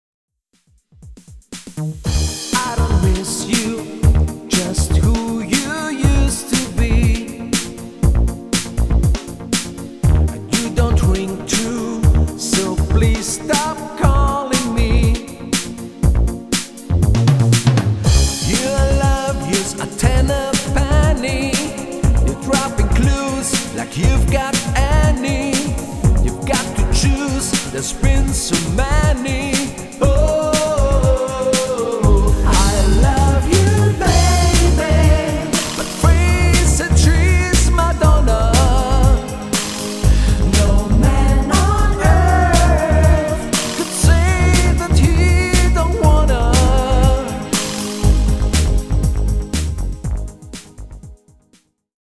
- das DUO klingt bereits wie eine ganze 5-Mann-Band
- ECHTE Live-Musik & Live-Gesang mit 2 SUPER Solostimmen
• Coverband